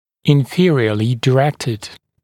[ɪn’fɪərɪəlɪ dɪ’rektɪd][ин’фиэриэли ди’рэктид]направленный вниз